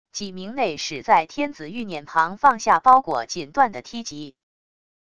几名内使在天子御辇旁放下包裹锦缎的梯级wav音频